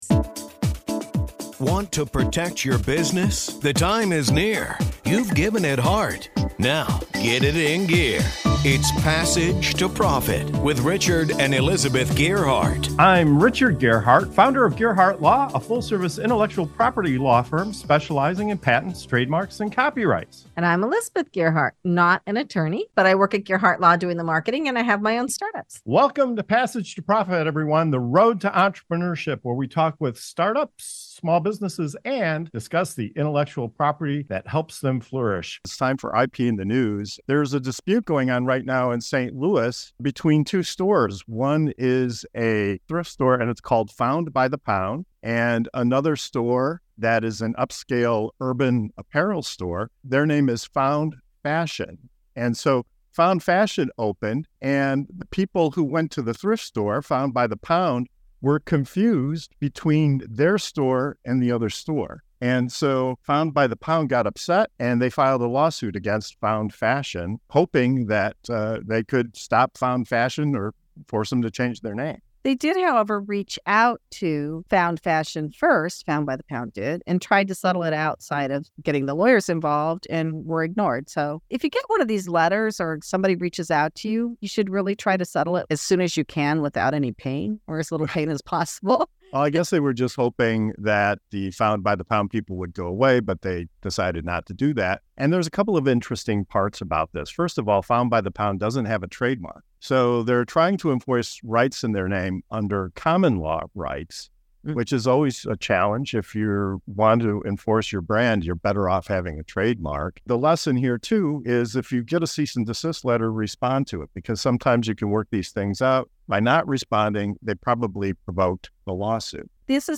Whether you're a seasoned entrepreneur, a startup, an inventor, an innovator, a small business or just starting your entrepreneurial journey, tune into Passage to Profit Show for compelling discussions, real-life examples, and expert advice on entrepreneurship, intellectual property, trademarks and more.